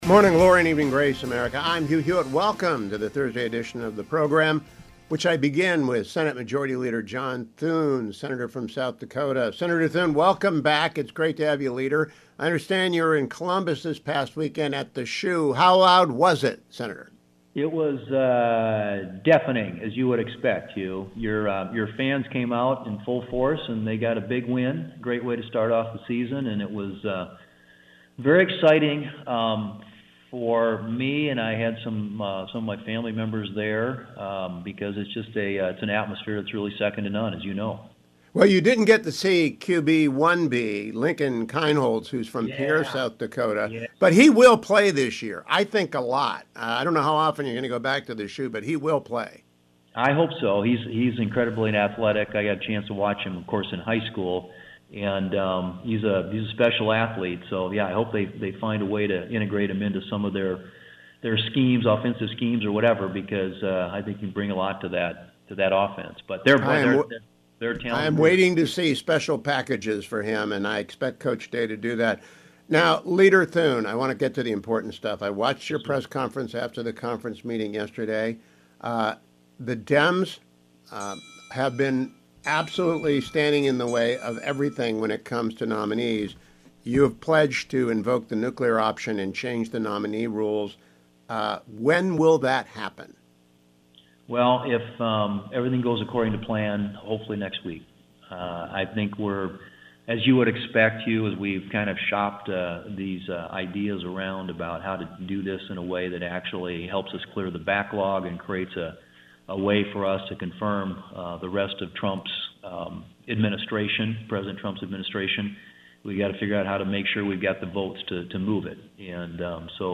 Senate Majority Leader John Thune joined me on the program today to discuss the rules changes the Senate GOP Conference have agreed to impose on the Senate’s confirmation processes via the precedent set by then Senate Majority Leader Harry Reid (D-NV) in 2013: